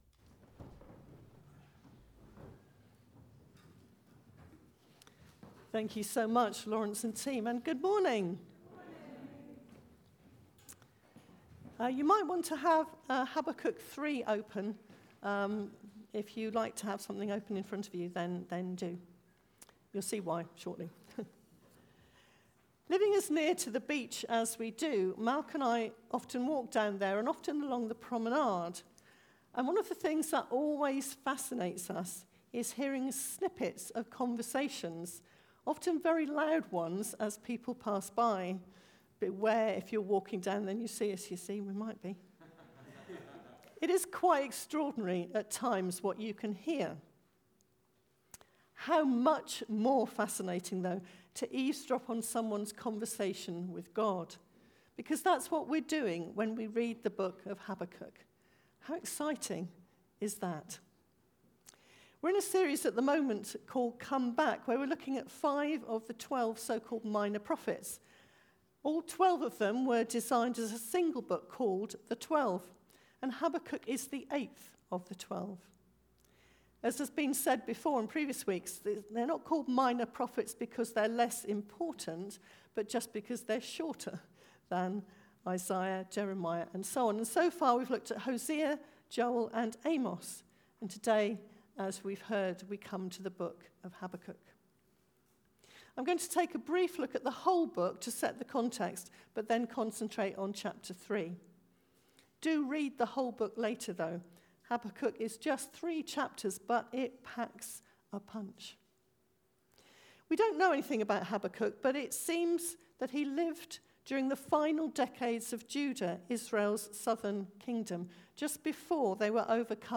Sermons From Christchurch Baptist Church (CBCDorset)